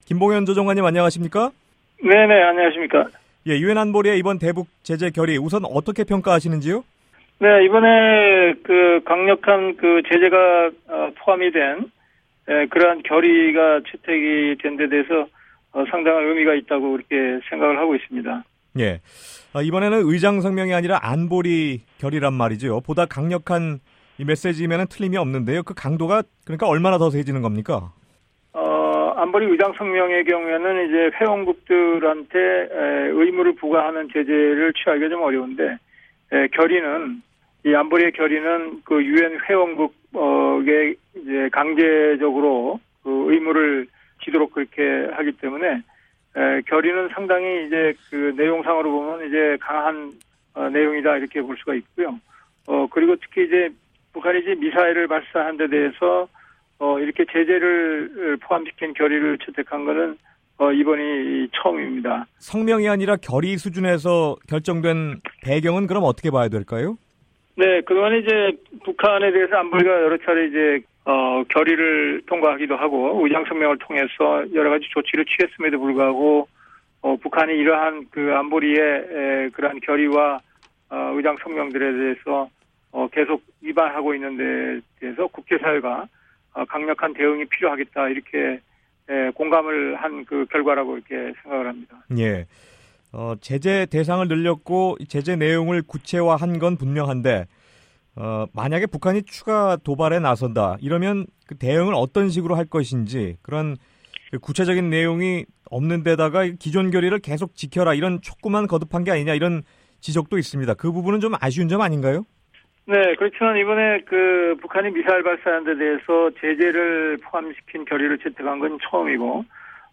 [인터뷰] 김봉현 한국 외통부 다자외교조정관